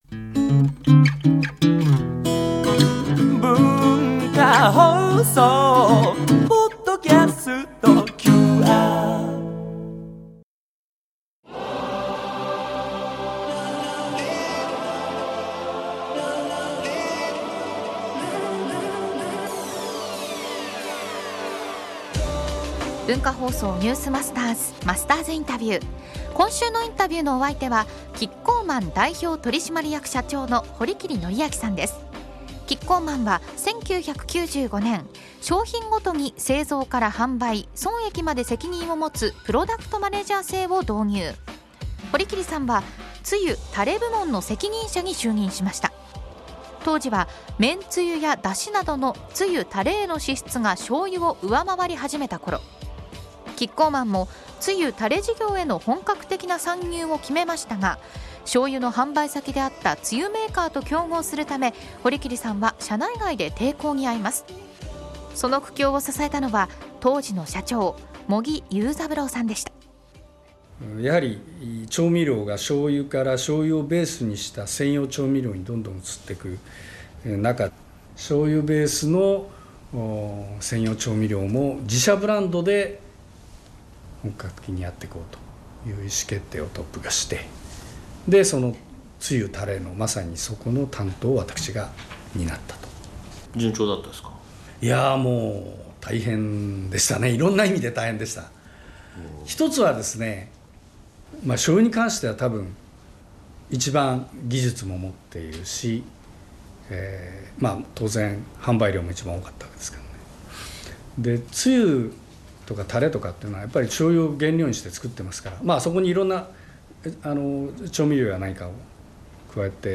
毎週、現代の日本を牽引するビジネスリーダーの方々から次世代につながる様々なエピソードを伺っているマスターズインタビュー。
（月）～（金）AM7：00～9：00　文化放送にて生放送！